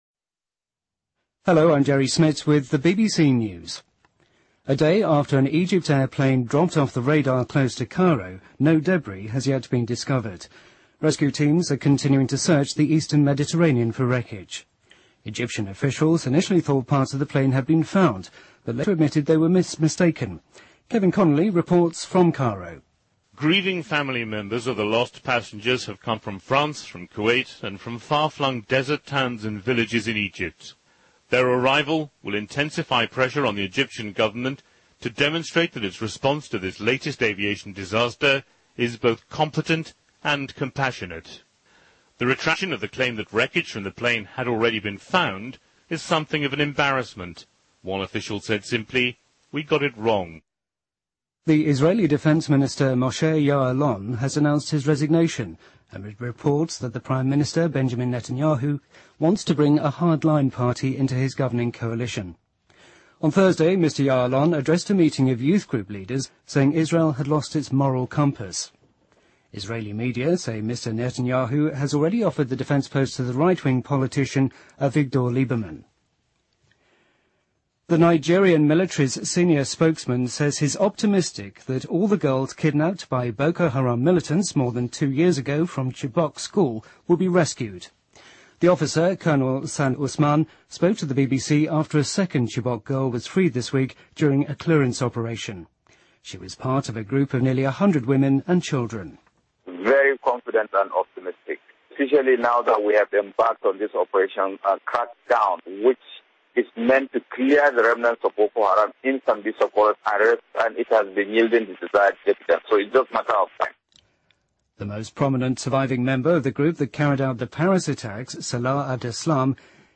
BBC news,美国科学家研发硬币大小无人机
日期:2016-05-31来源:BBC新闻听力 编辑:给力英语BBC频道